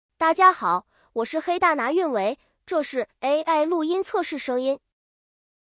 AI配音神器，热门声音模型助力创作！
各种声音模型都可以用，并且可以调节倍速下载，下面是我随即挑选的几款声音预览：